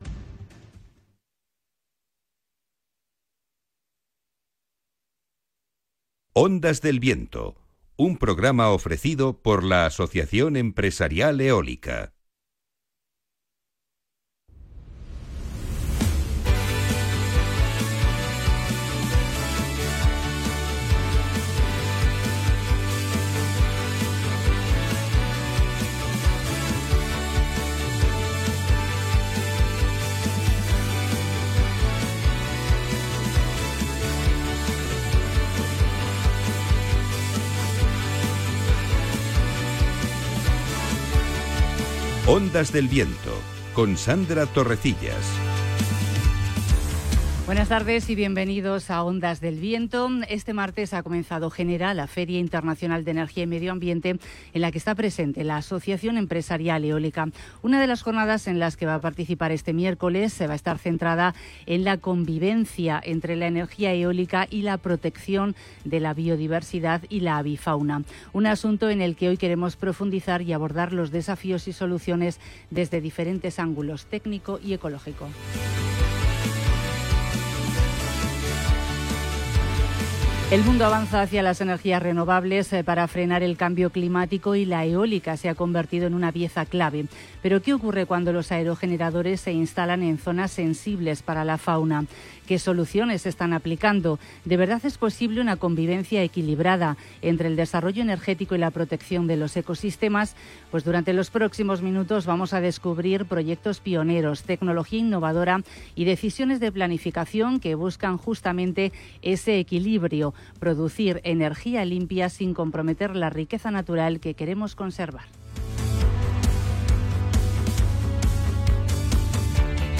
el programa radiofónico del sector eólico